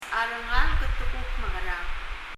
rengalek　　[rɛŋʌlɛk]　　　子供達 children